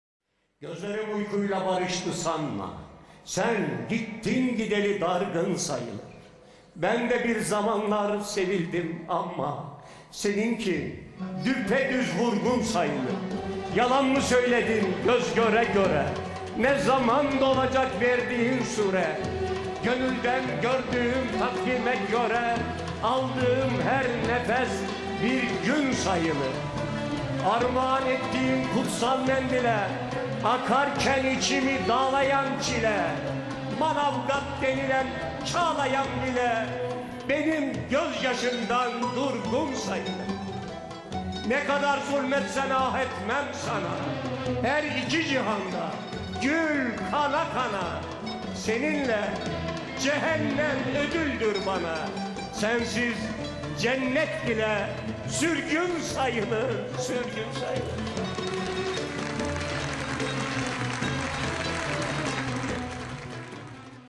cemal safi vurgun şiir dinletisi
umut dalları:trt arşivinden alıntıdır